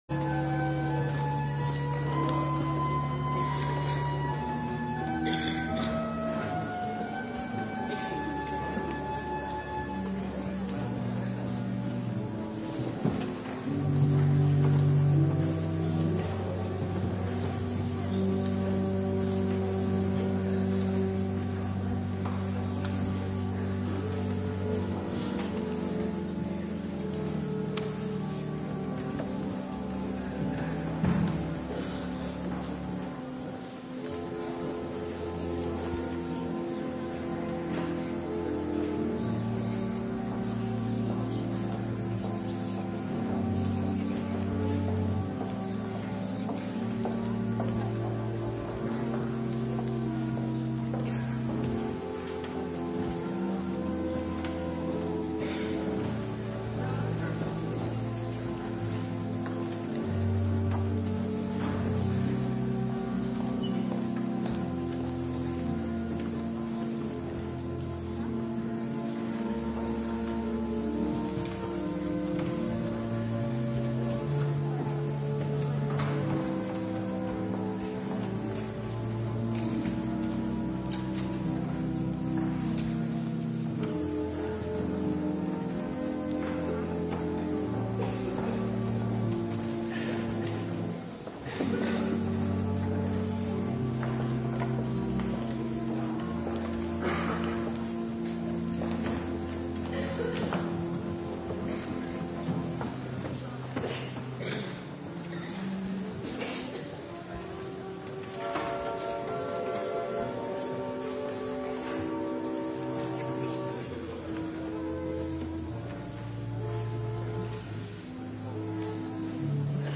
Morgendienst Eerste Kerstdag
Locatie: Hervormde Gemeente Waarder